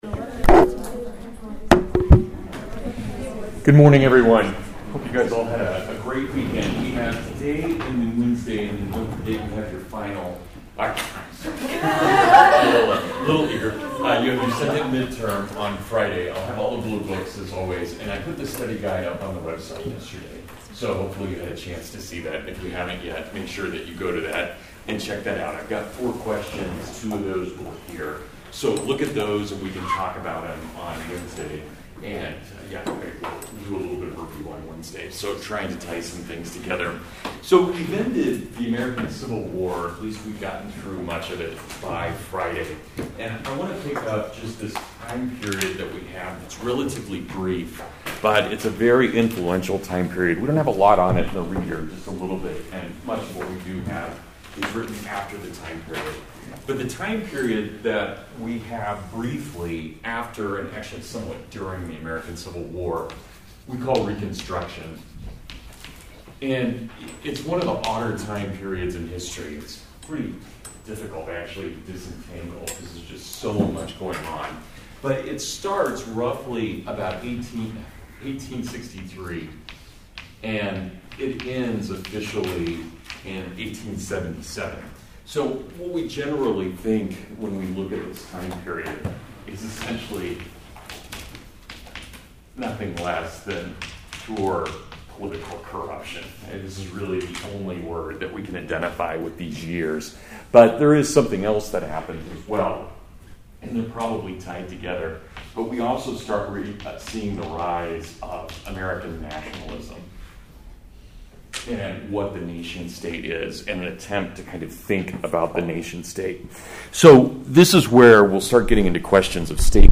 Reconstruction (Full Lecture)